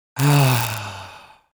Sighs Male 01
Sighs Male 01.wav